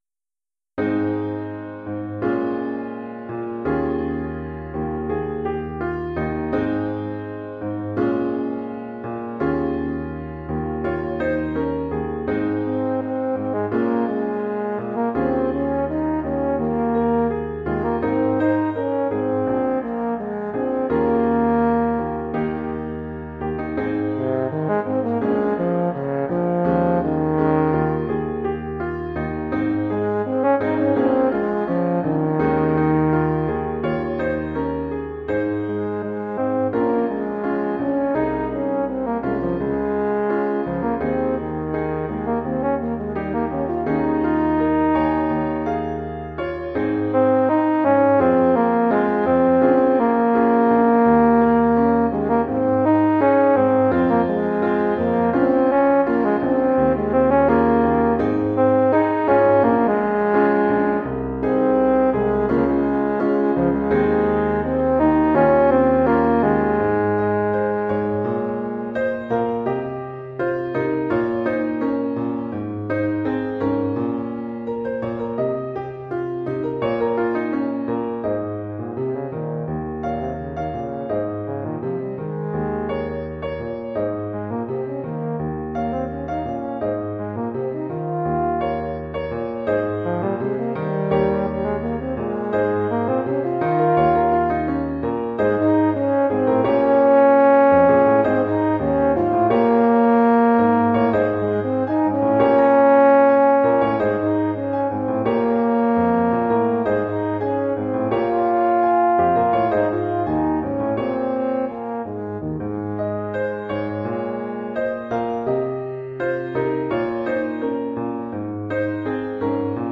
Saxhorn basse-Tuba